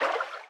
Sfx_creature_symbiote_swim_slow_07.ogg